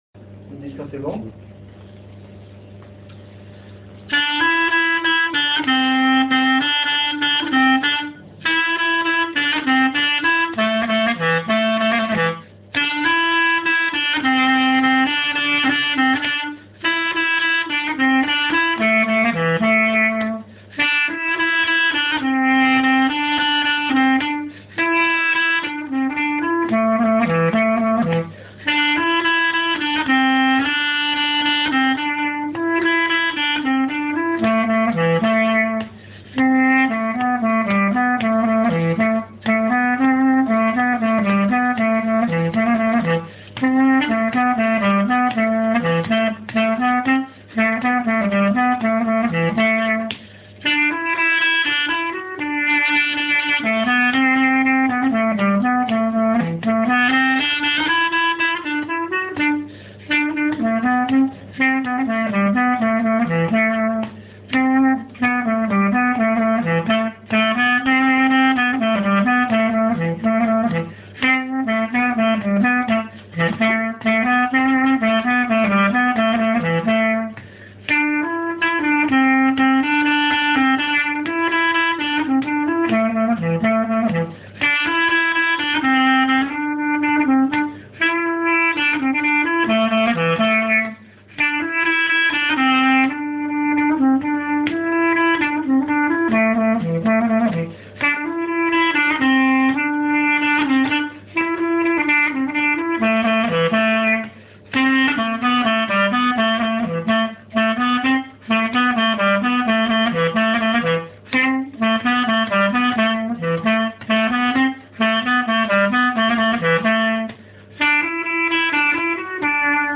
turc_1.mp3